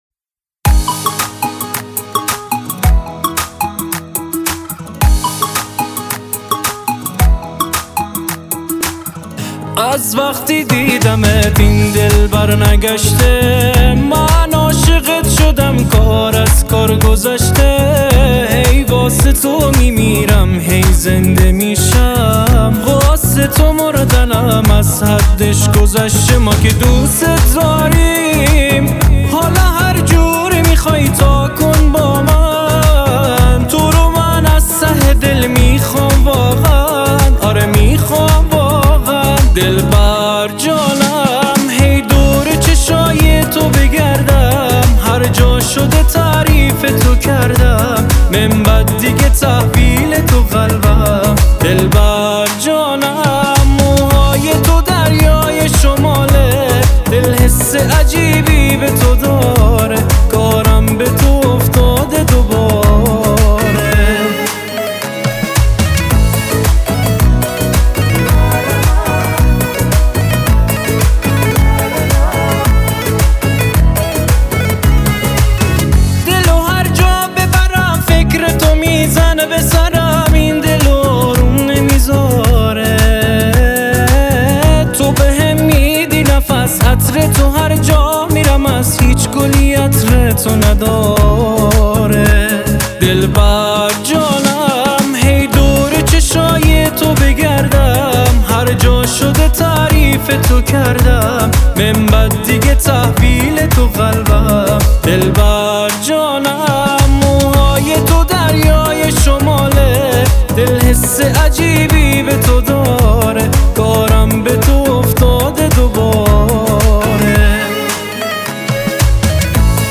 افغانی